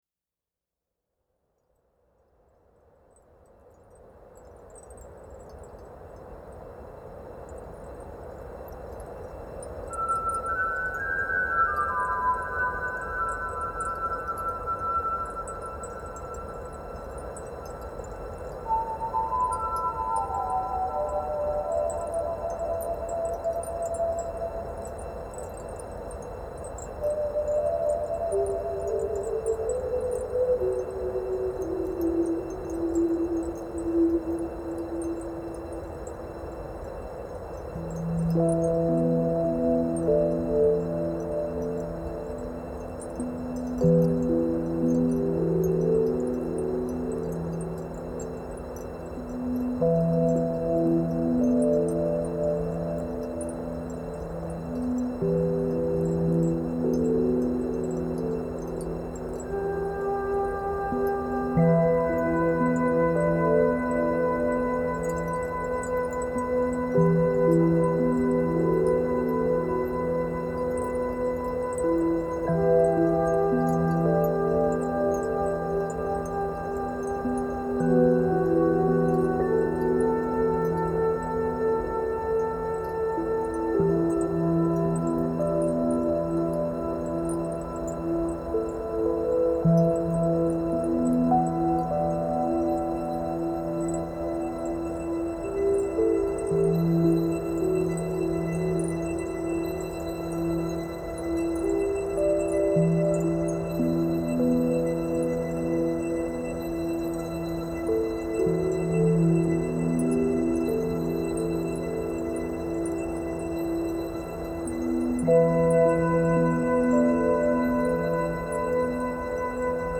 Nueva era